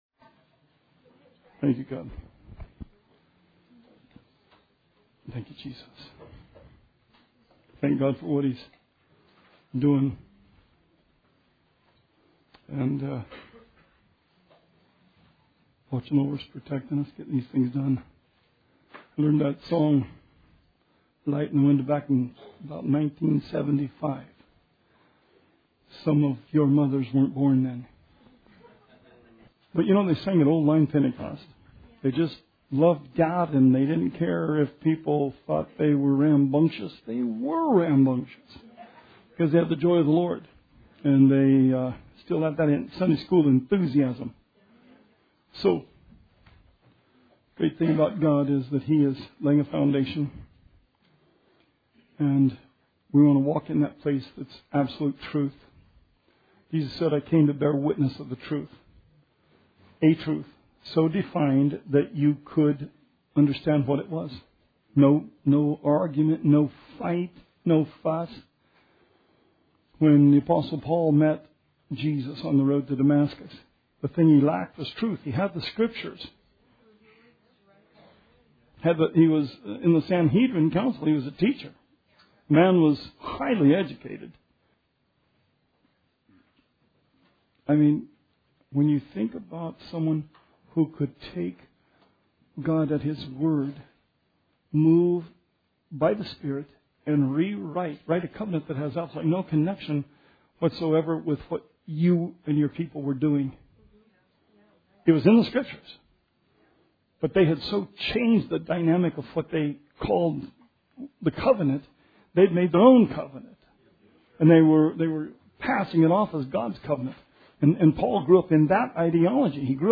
Bible Study 8/10/16